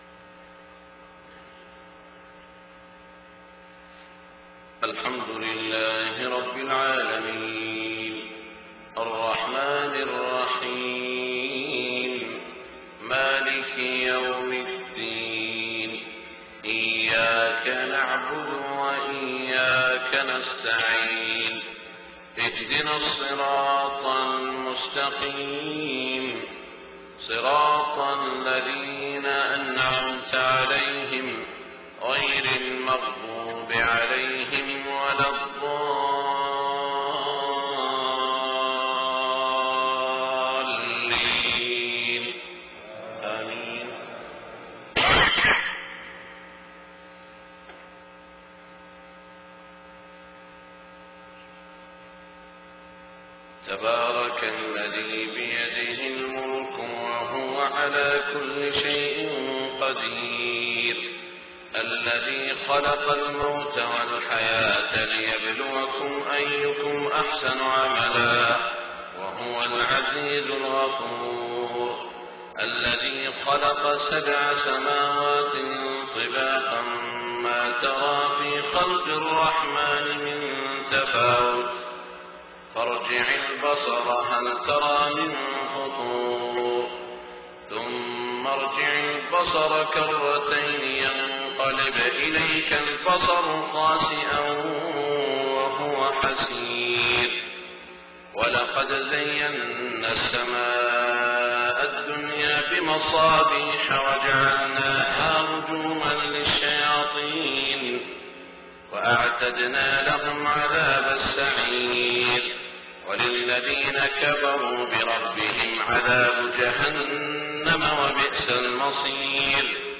صلاة الفجر 1-7-1426 سورة الملك > 1426 🕋 > الفروض - تلاوات الحرمين